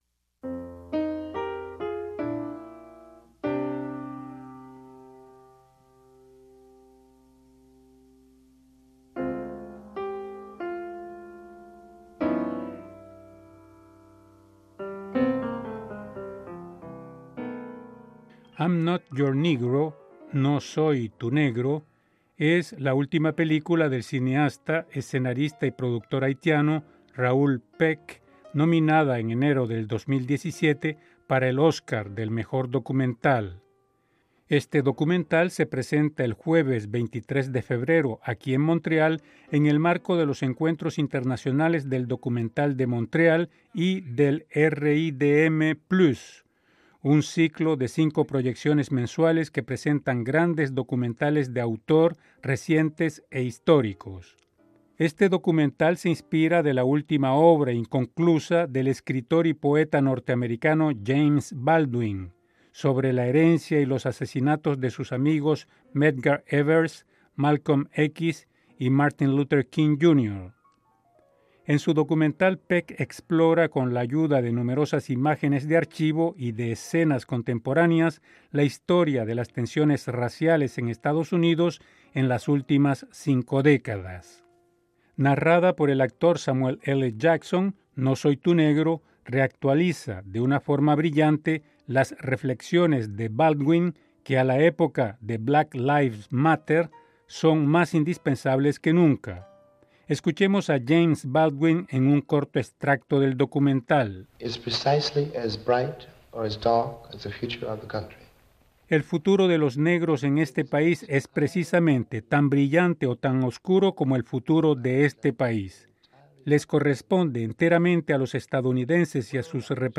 fueron entrevistados